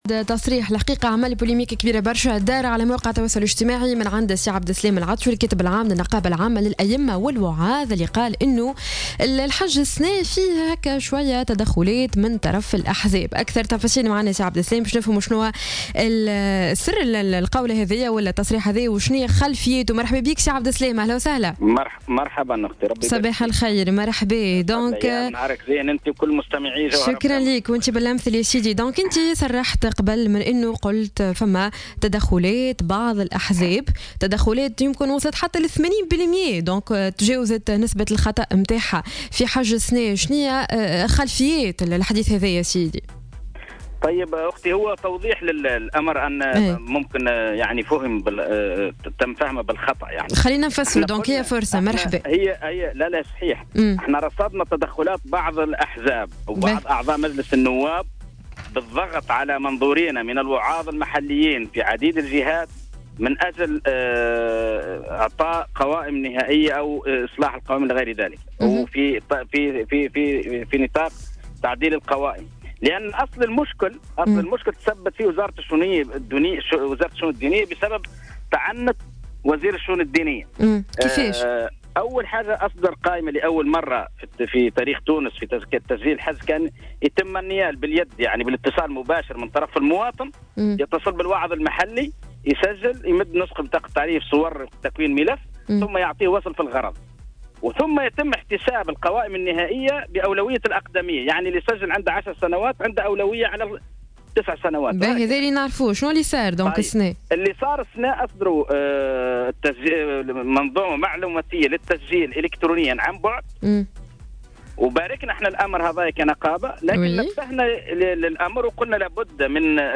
مداخلة له على الجوهرة "اف ام"